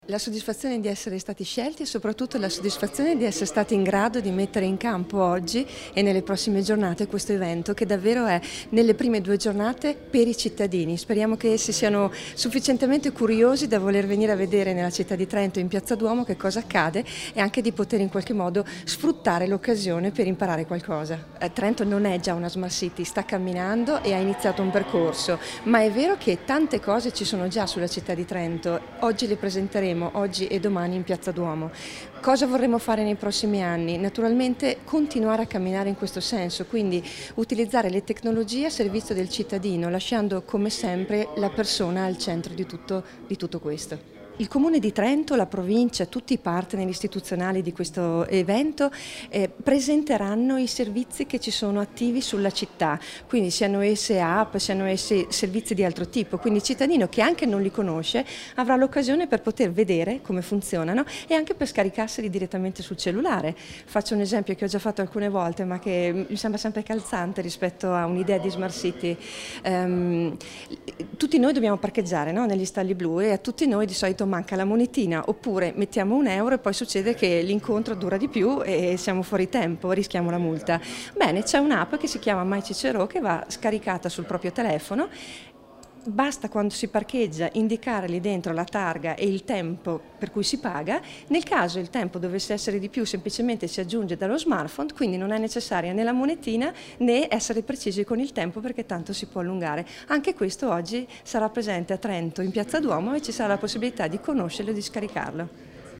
L'inaugurazione con l'assessora provinciale Ferrari e il sindaco Andreatta con l'assessora Maule